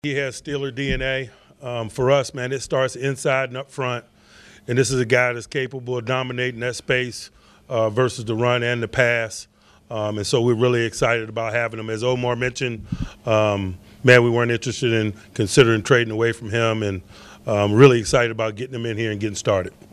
Coach Mike Tomlin says Harmon is a perfect fit for the Steelers.